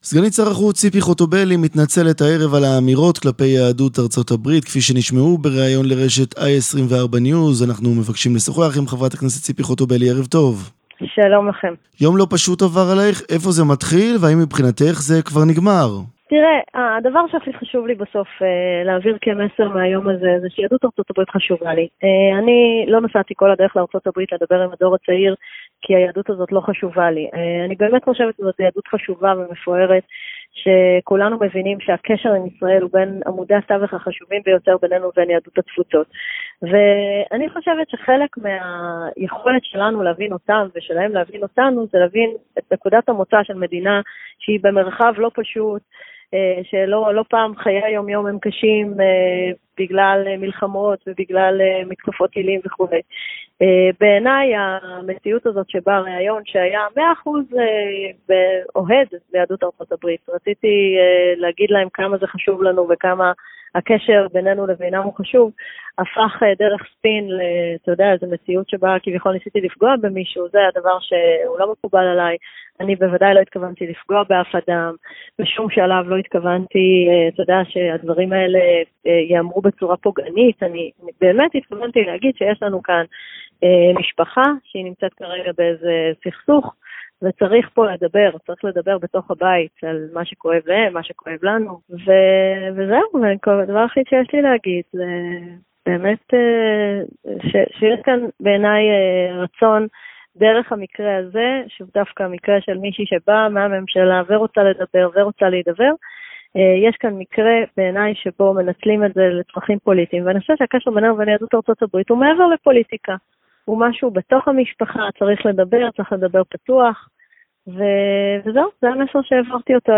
Заместитель министра иностранных дел Ципи Хотовели объяснила в беседе с корреспондентом 7 каналазамечания, высказанные в адрес американских евреев.